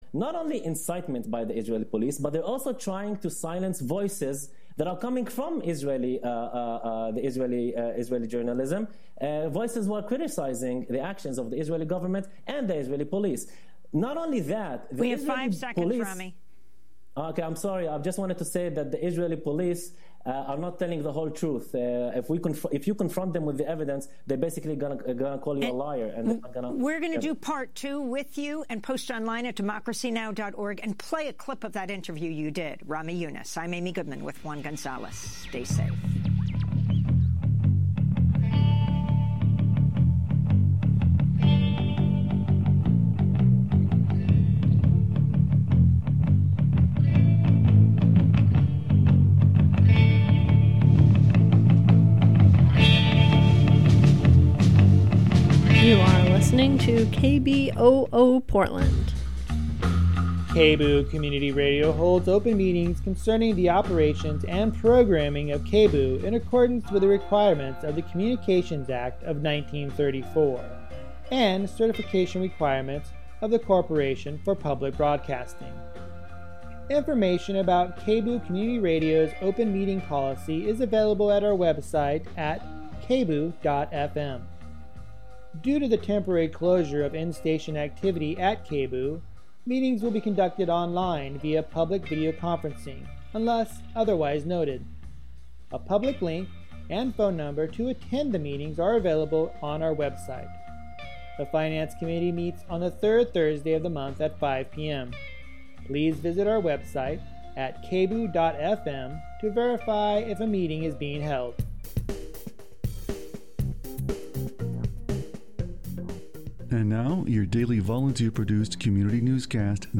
Download audio file Palestinian residents of Portland and their supporters held a rally and march held May 15th, 2021 in Terry Schrunk Plaza in downtown Portland to call for an end to the Israeli assault on Gaza, and an end to the Israeli military occupation of Palestinian land.